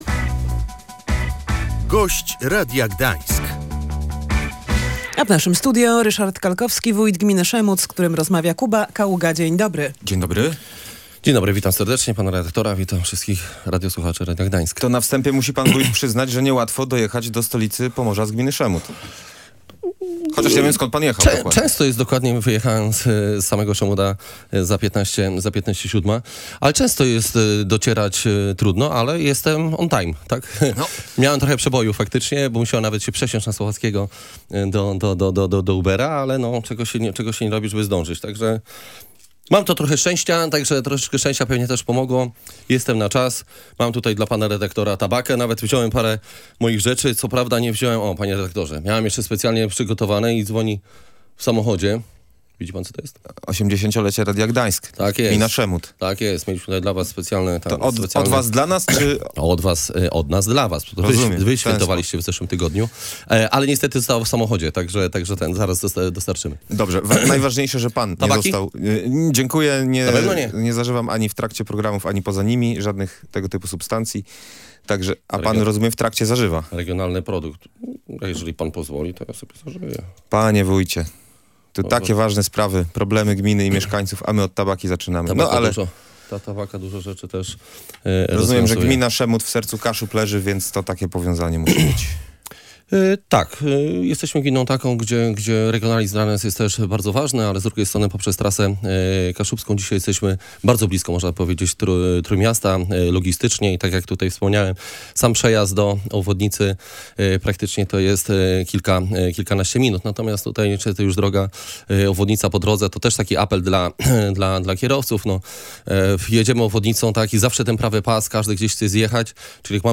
Teraz właściciel złożył wniosek do samorządu, który jest procedowany – mówił w Radiu Gdańsk wójt gminy Szemud Ryszard Kalkowski.